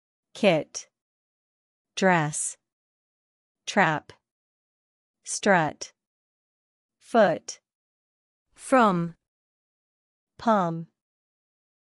Short Vowels
/ɪ/ kit Like a quick “ee.”
/æ/ trap Wide smile sound.
short-vowels.mp3